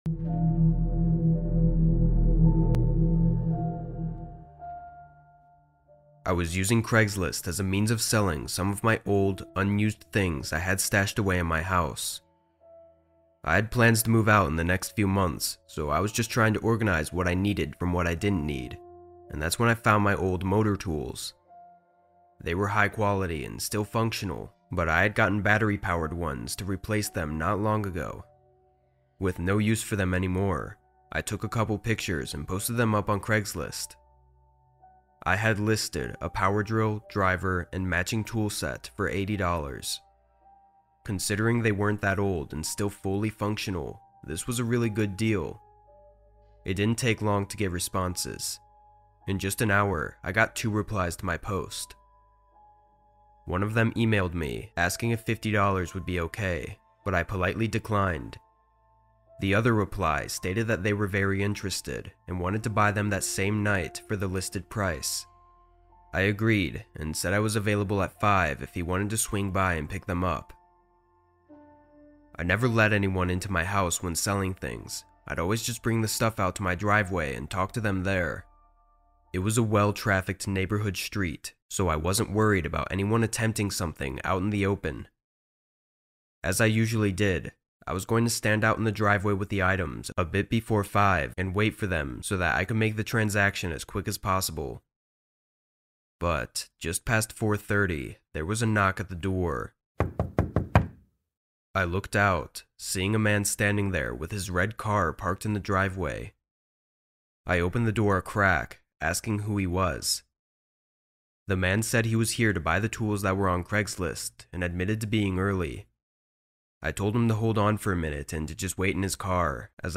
3+ Hours of True Night-Shift Horror Stories Told in the Rain